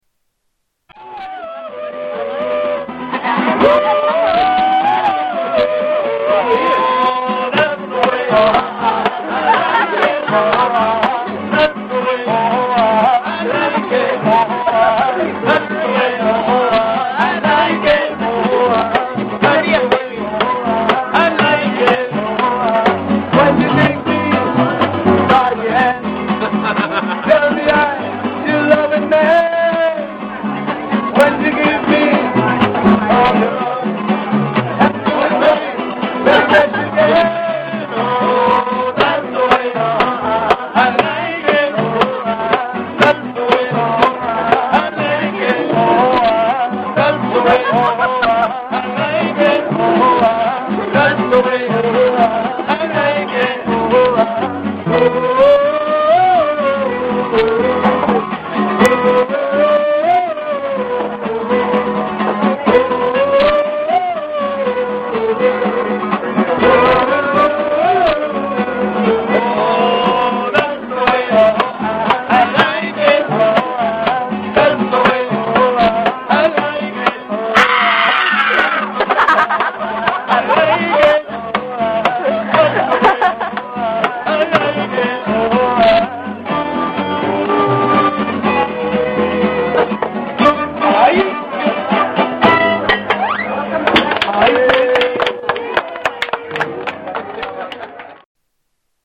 Mariachi Band